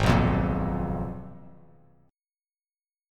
Abm6add9 chord